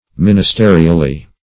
Ministerially \Min`is*te"ri*al*ly\, adv.